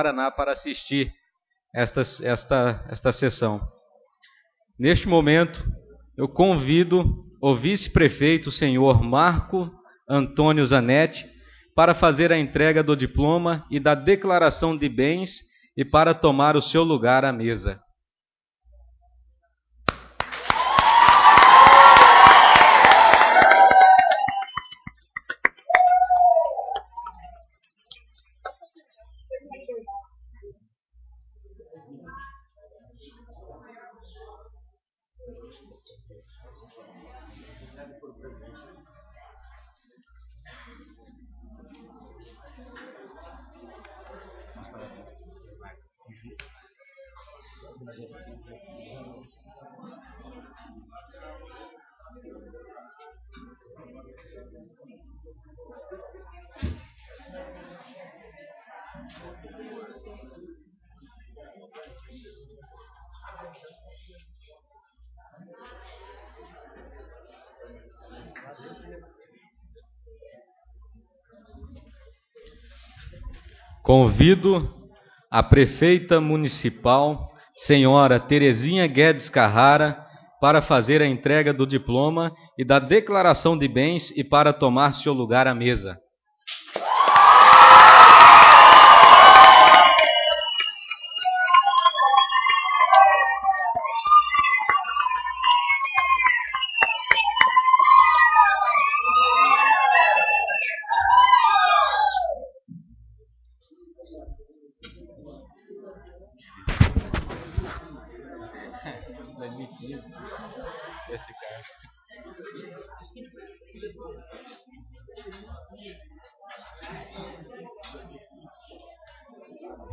Sessão Solene de Posse da Prefeita e Vice-Prefeito 01/01/2017